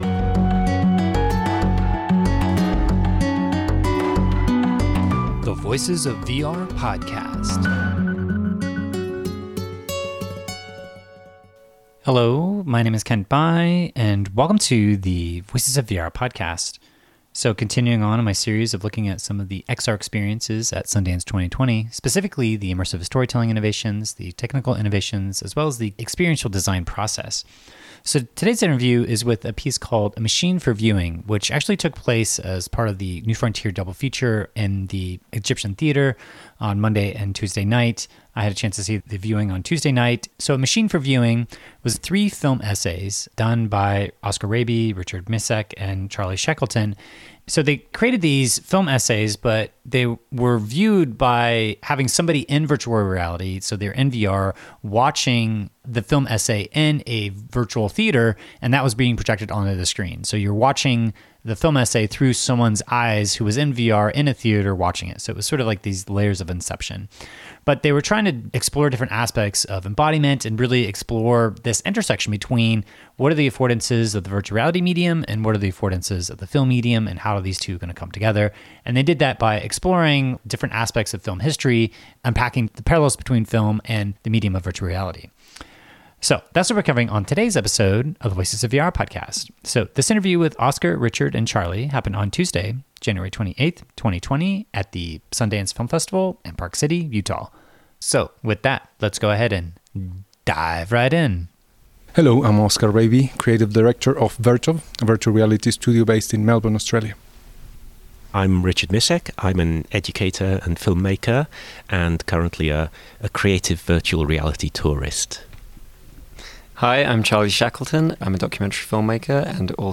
I had a chance to talk with the three lead artists just after their final screening at Sundance 2020 to explore what film can learn from VR, and what VR can learn from film.